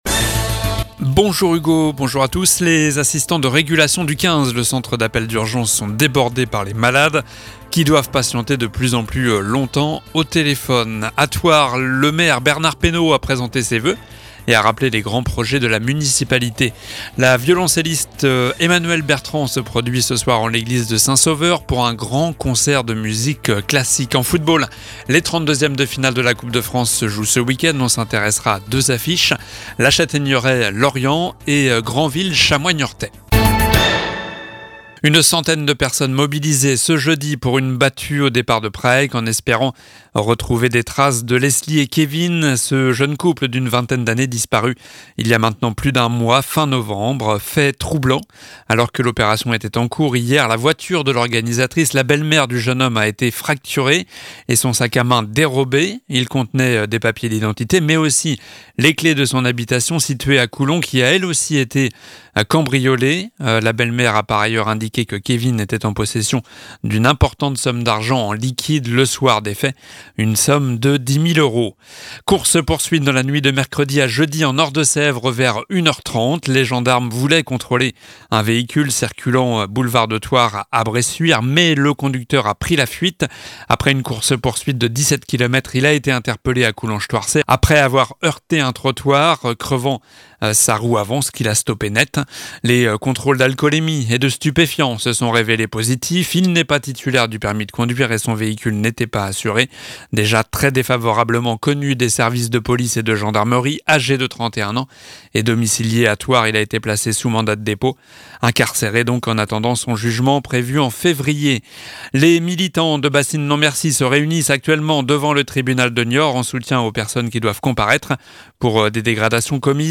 Journal du vendredi 6 janvier (midi)